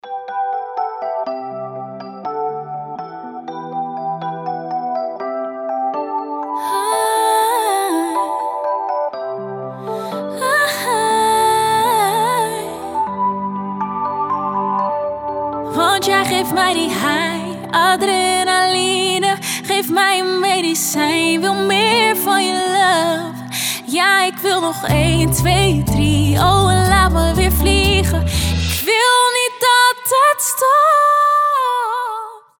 спокойные
красивый женский голос
нидерландские